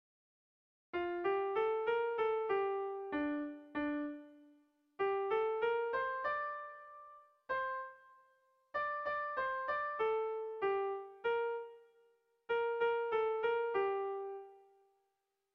Kontakizunezkoa
Lauko txikia (hg) / Bi puntuko txikia (ip)
AB